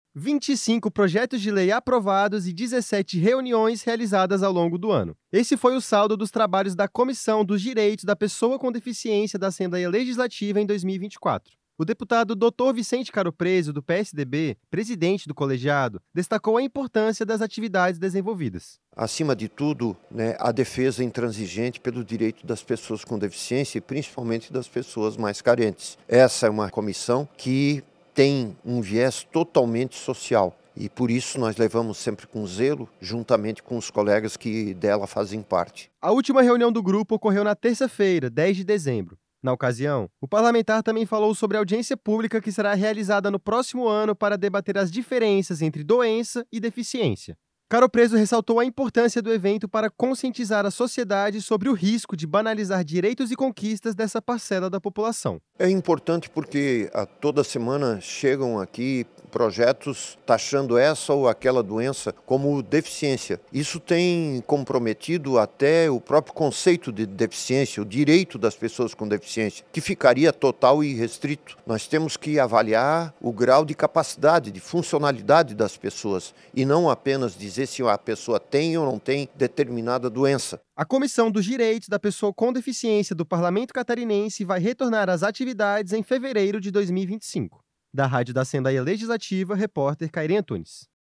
Entrevista com:
- deputado Dr. Vicente Caropreso (PSDB), presidente da Comissão dos Direitos da Pessoa com Deficiência.